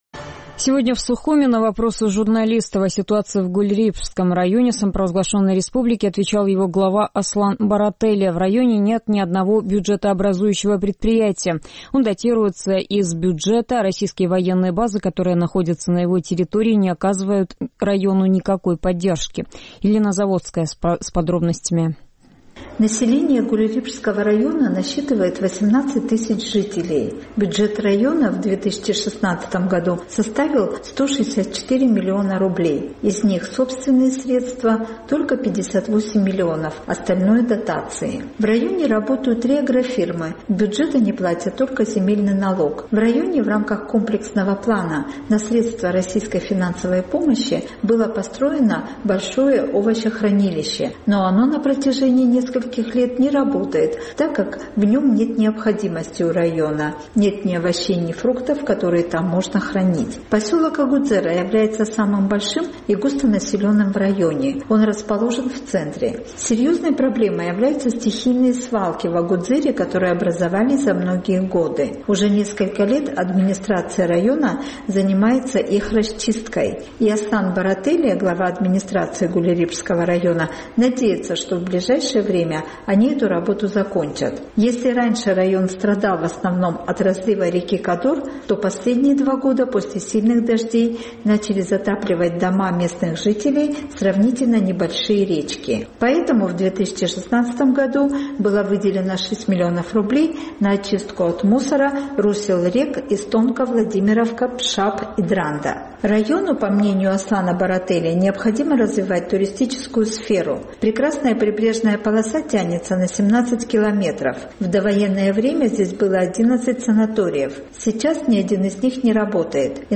Сегодня в Сухуме в АРСМИРА на вопросы журналистов о ситуации в Гульрипшском районе отвечал его глава Аслан Барателия.